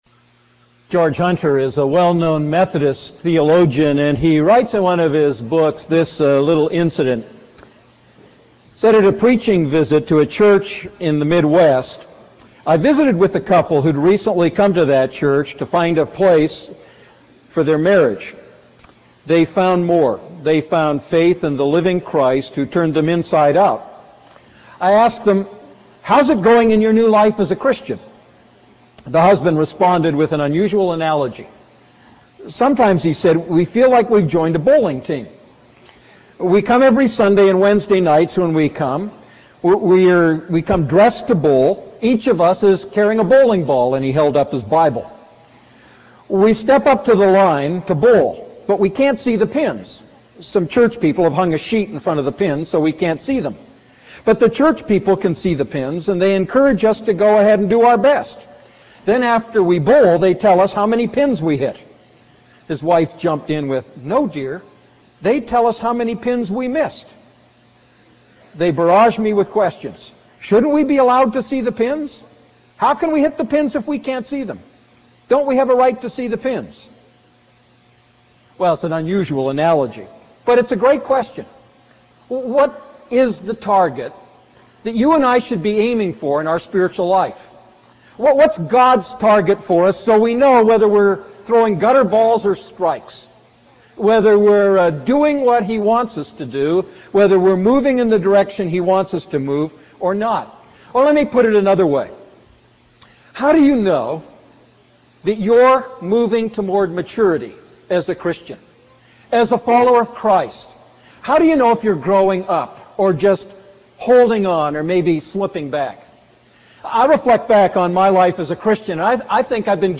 A message from the series "1 Thessalonians."